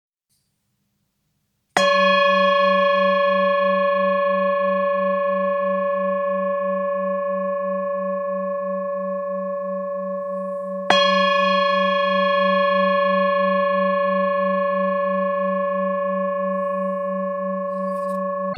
bol full moon fa#
bol-full-moon-fa.mp3